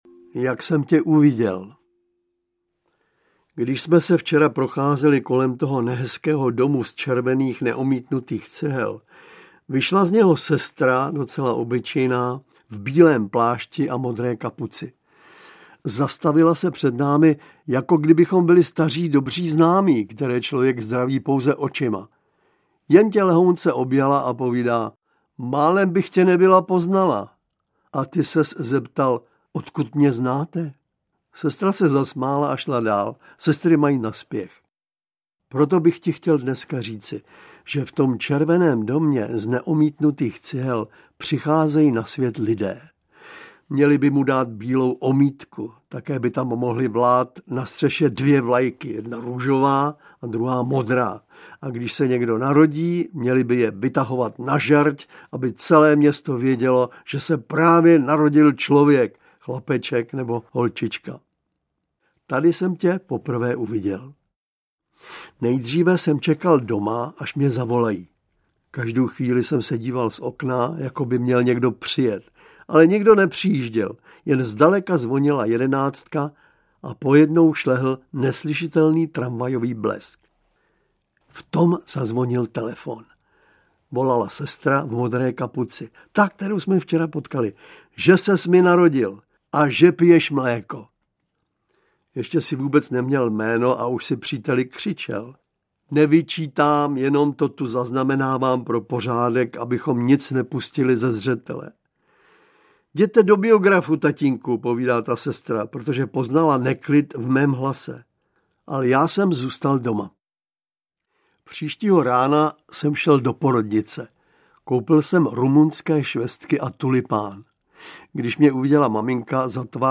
Dětské etudy audiokniha
Ukázka z knihy